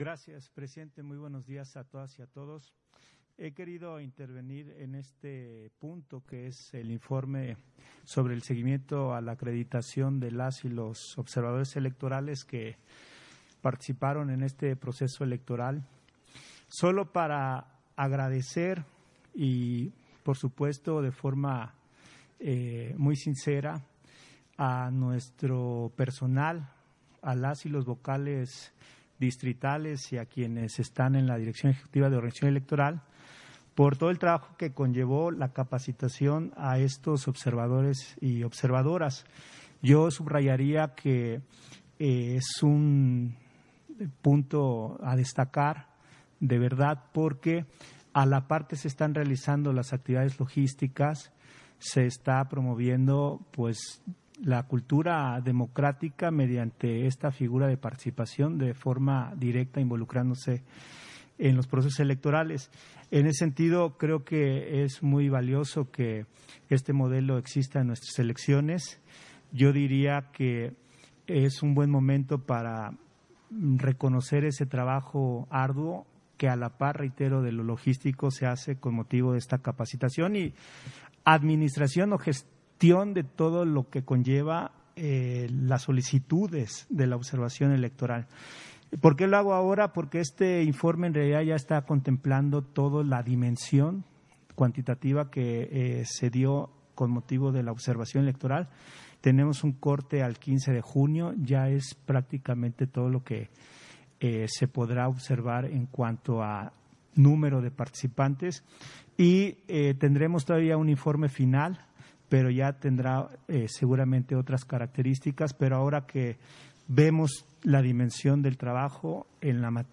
180621_AUDIO_INTERVENCIÓN-CONSEJERO-RUIZ-PUNTO-3-SESIÓN-EXT.